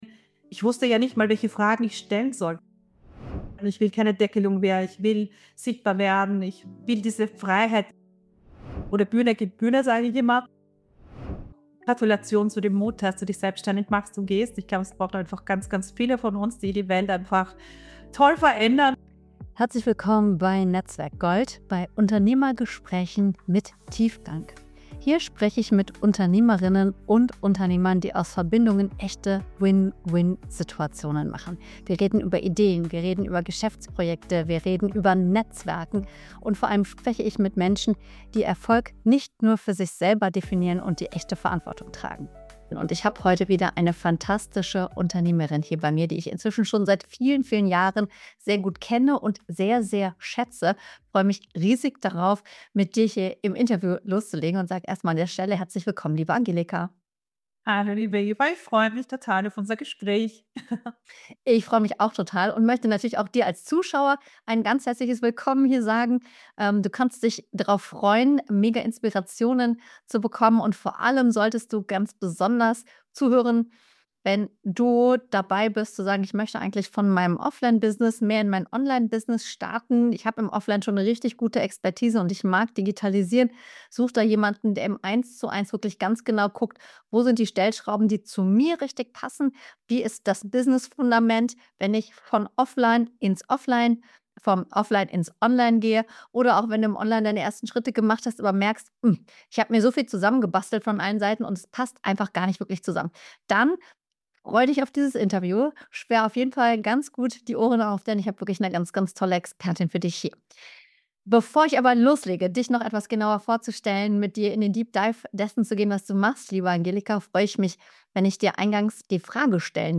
Willkommen beim NetzwerkGold Podcast – Unternehmergespräche mit Tiefgang.